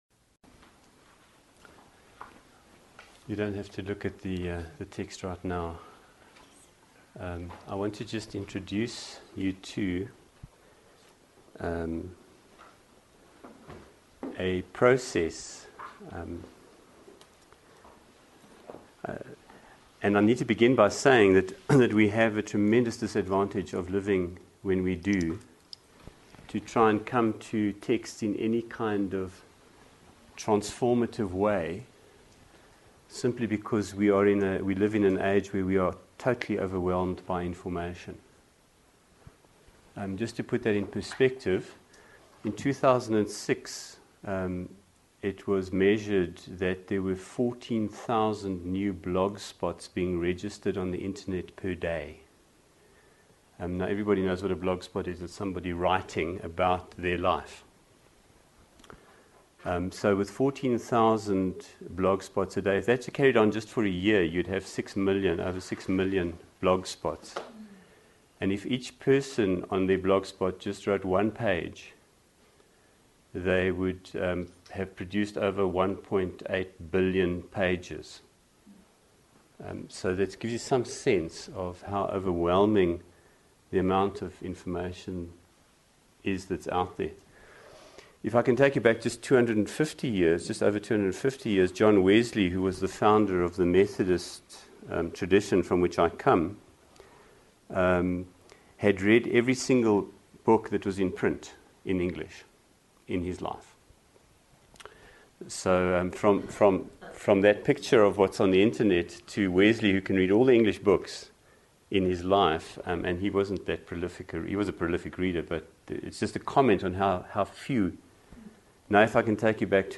This recording is from our first SMS residential retreat in 2008 at Dharmagiri in South Africa.